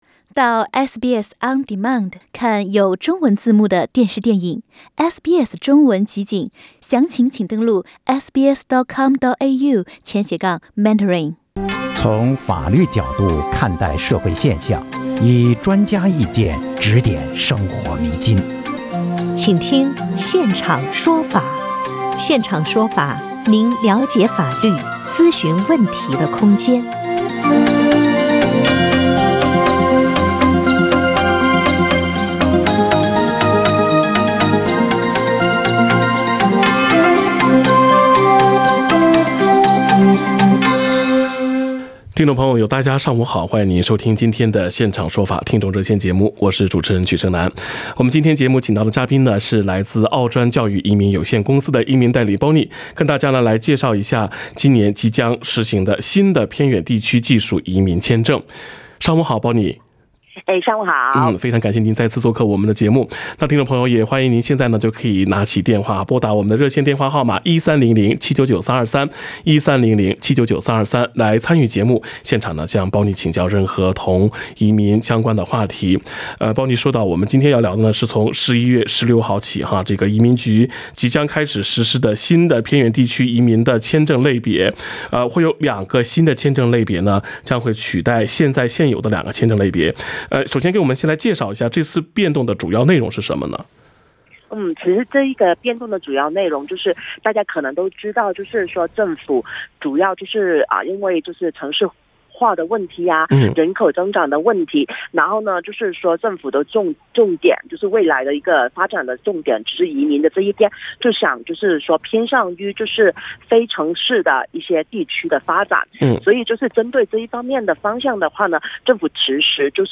《现场说法》听众热线逢周二上午8点30分至9点播出。